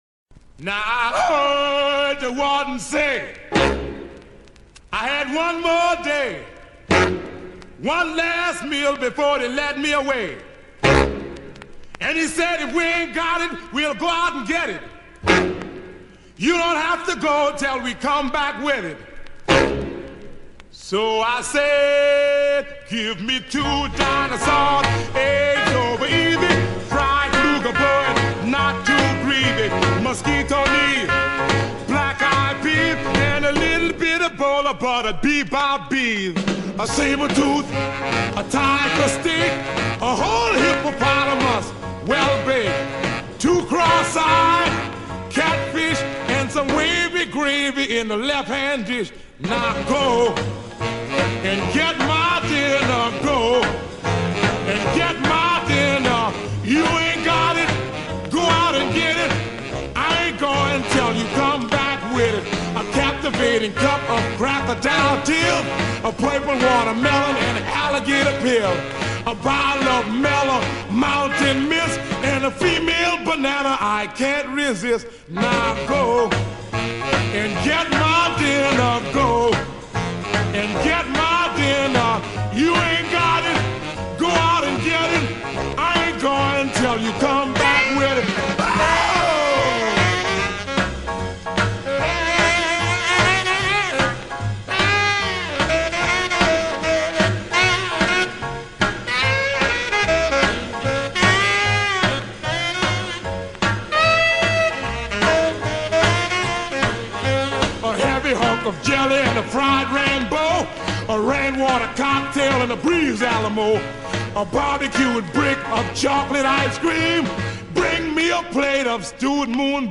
A singer, pianist and songwriter